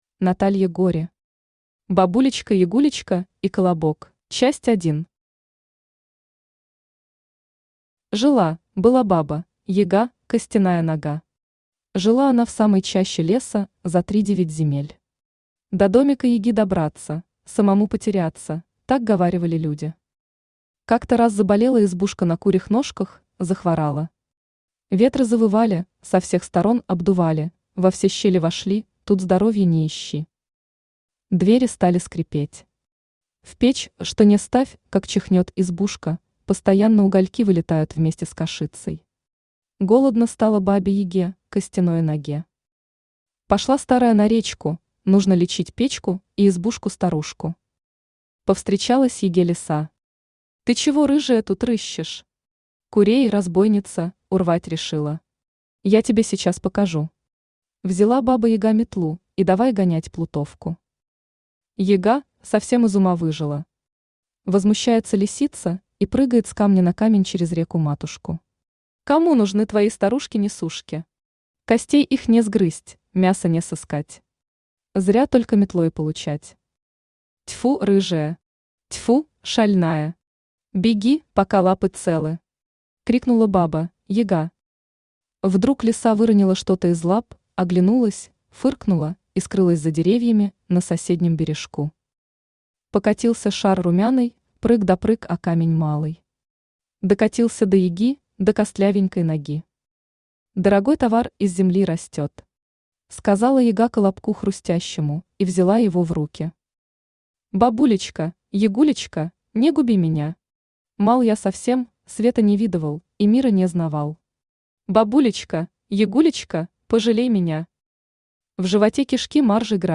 Читает: Авточтец ЛитРес
Аудиокнига «Бабулечка-Ягулечка и Колобок».